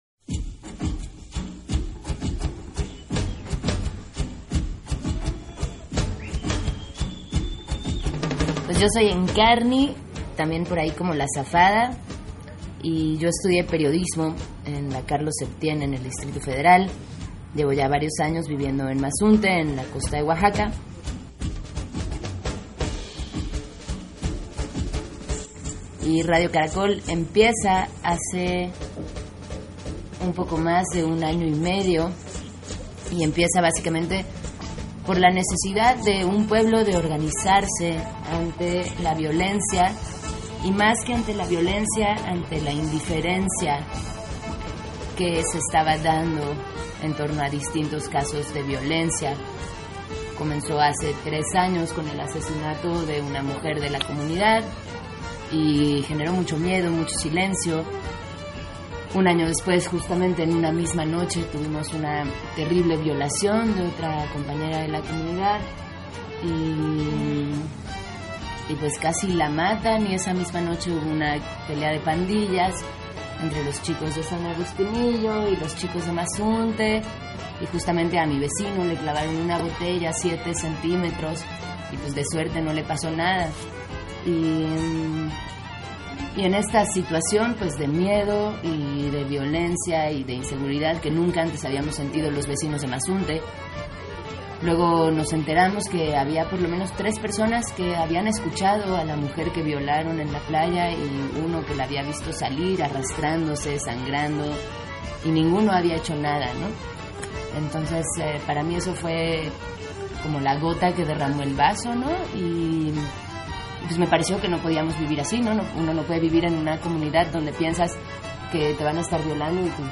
Entrevista
entrevista_Radio_Caracol_la_Voz_mazunte.mp3